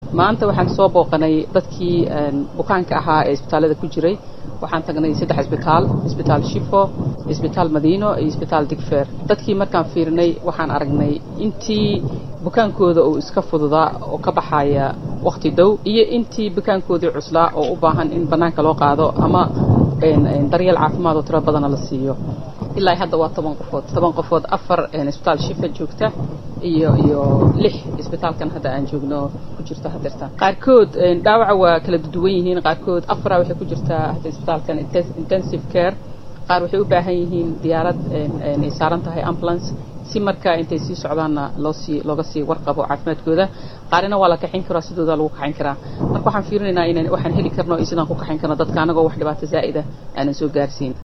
Wasiirka Wasaaradda Caafimaadka ee Xukuumadda Soomaaliya Xaamo Maxamed Xasan oo la hadashay booqashada kaddib Warbaahinta Qaranka ayaa ugu horeyn ka tacsiyadeysay dadkii shalay ku dhintay qaraxii ka dhacay Muqdisho, iyadoo kuwa dhaawac ahna Alle uga bariday in uu bogsiiyo.